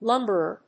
音節lúm・ber・er 発音記号・読み方
/‐bərɚ(米国英語), ‐bərə(英国英語)/